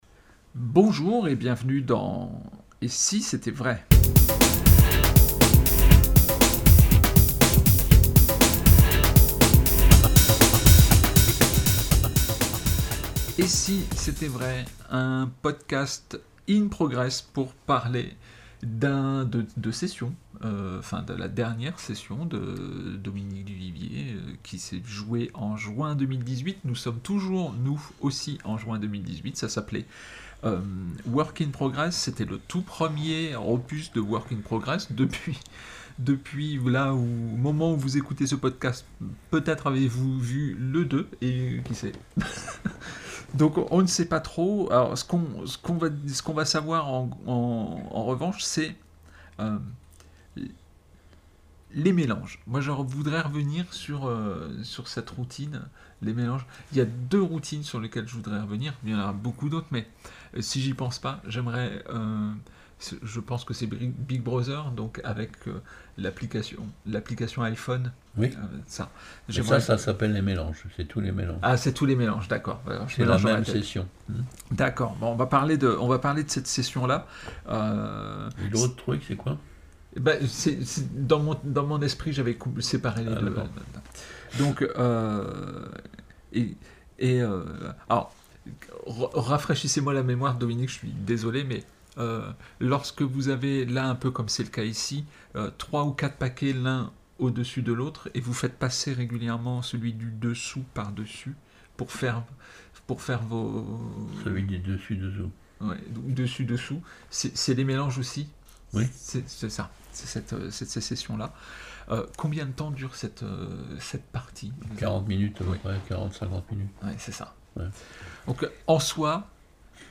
Podcast « Et si c’était vrai ? » émission n°77 - Entretiens